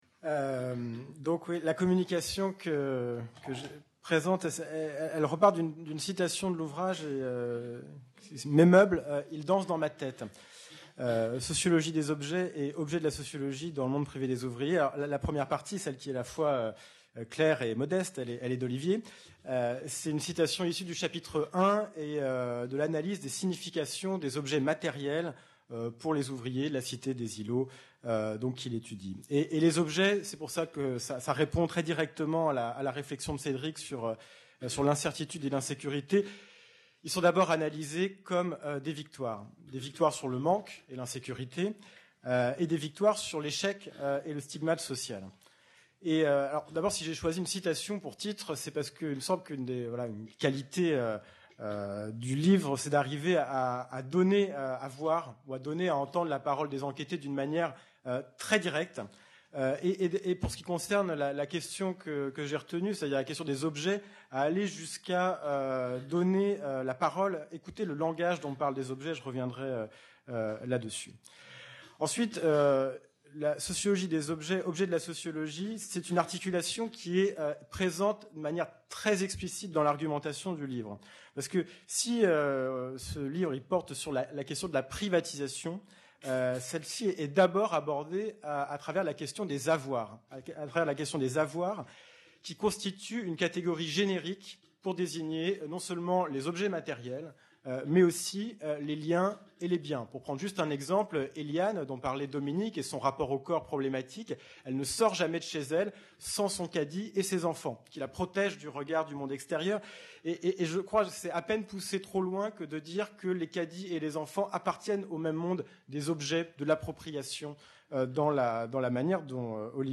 Table-ronde 2 : Transversales